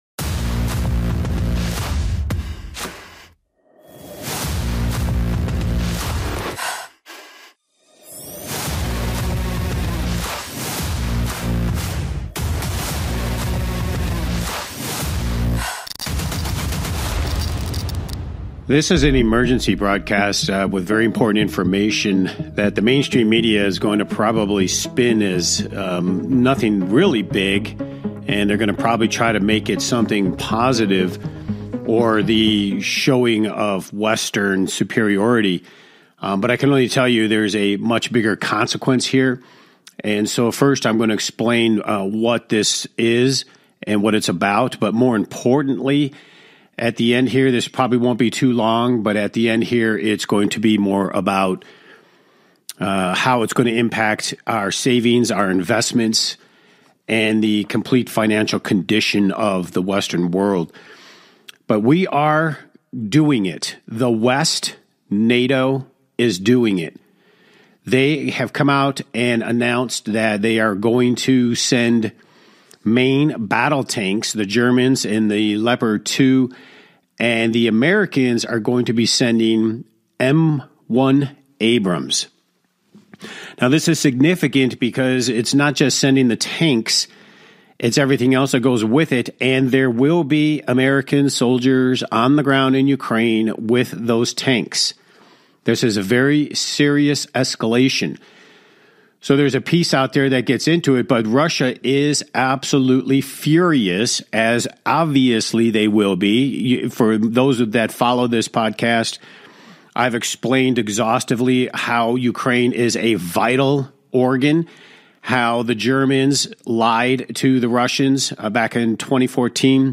Talk Show Episode, Audio Podcast, Rigged Against You and Emergency Broadcast on , show guests , about Emergency Broadcast, categorized as Business,Investing and Finance,News,Politics & Government,Society and Culture,Technology